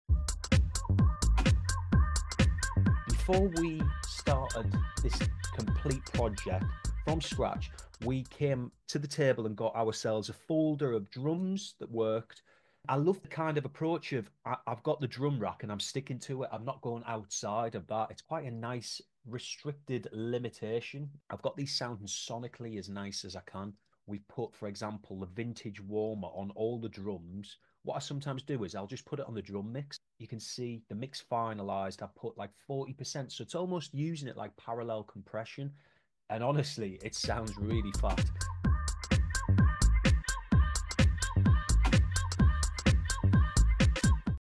We knew it was going to sound good as we choose great drum sounds to start with 💥 Full tutorial on making this track available to members now 💣 Building Your Own Drum Rack Sound Effects Free Download.